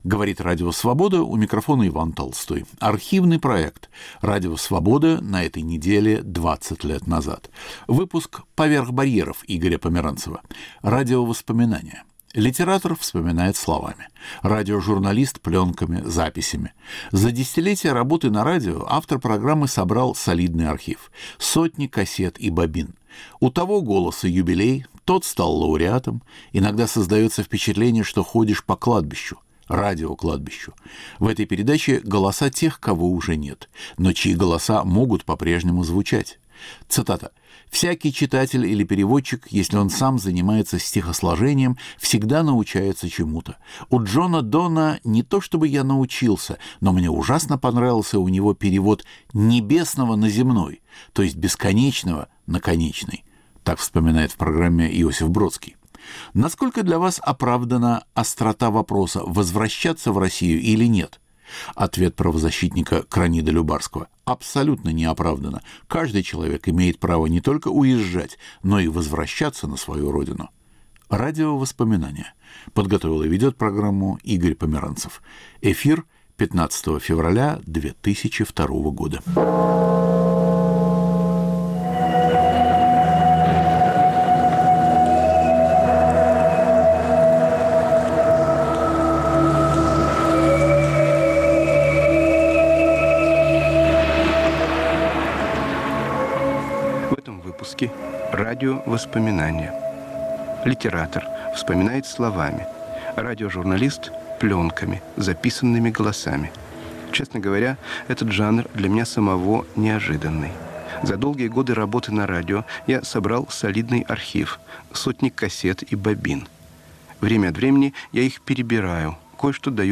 Игорь Померанцев представляет записи из своего аудио-архива - голоса людей, которых уже нет.